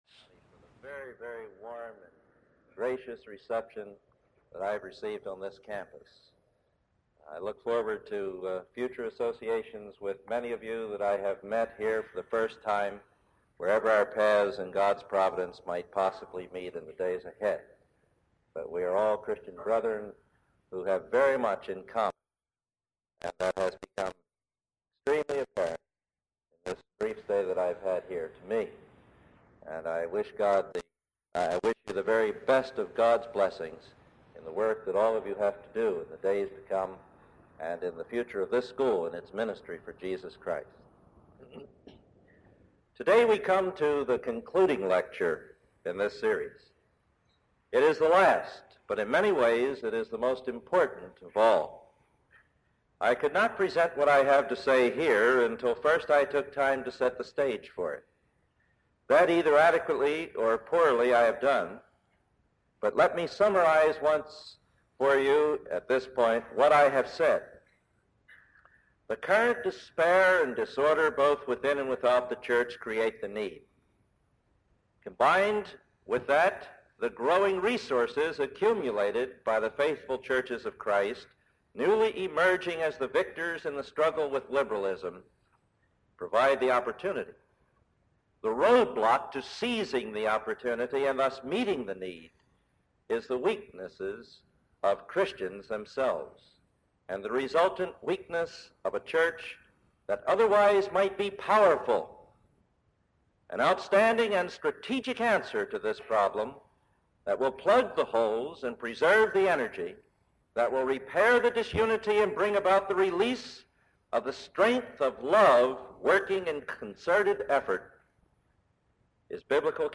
four different lectures on the Scriptures and Counseling at the 3rd Annual Staley Distinguished Christian Scholar Lectureship Program at Cedarville College; this is Part 4 of 4.